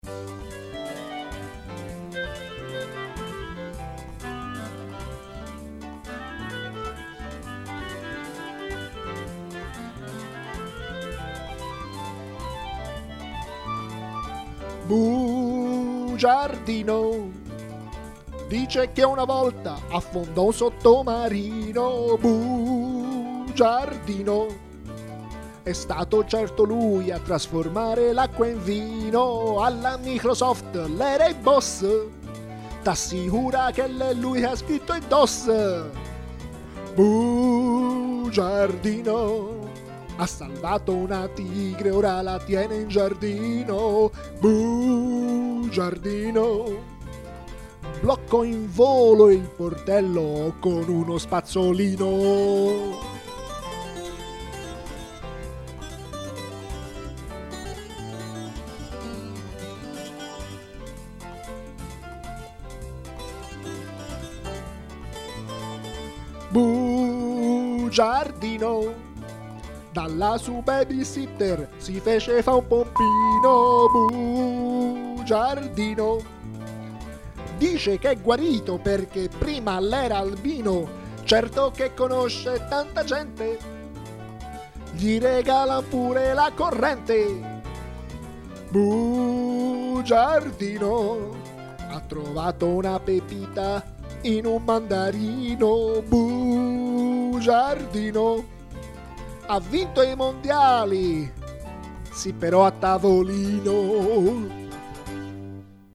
Molto rilassata